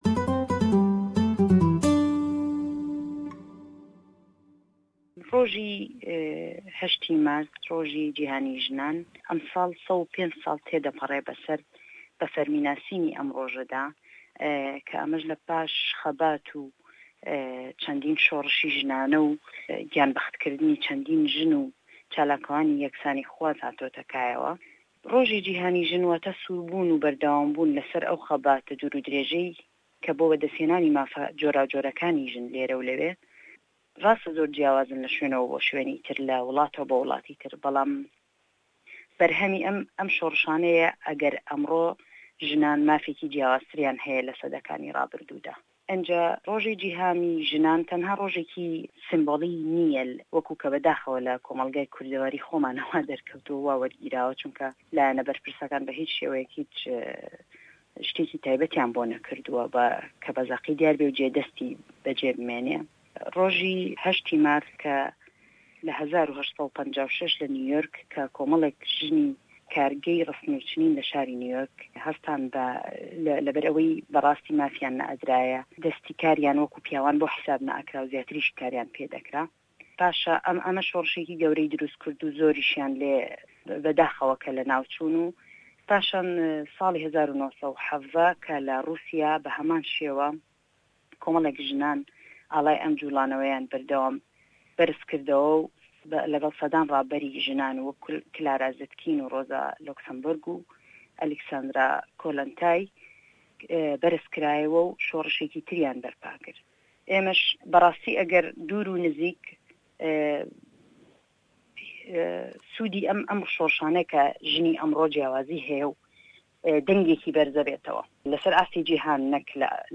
hevpeyvîne